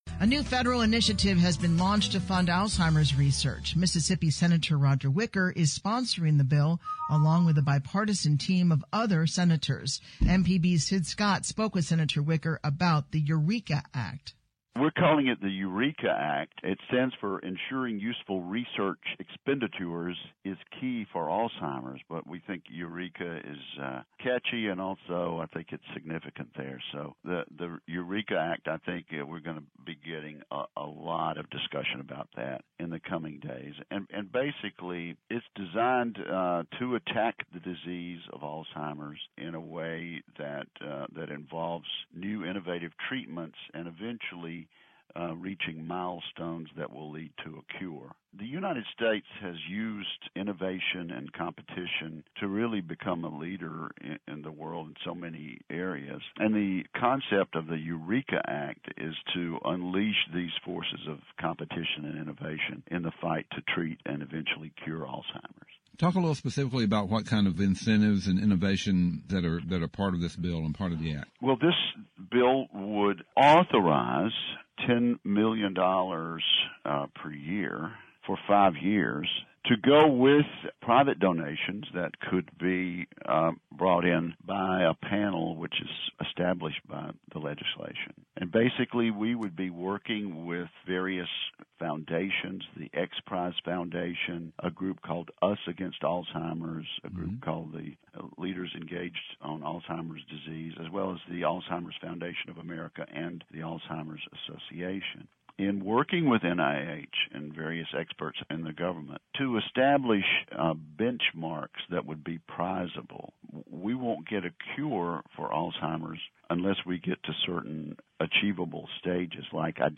Wicker Discusses EUREKA Act with Mississippi Public Broadcasting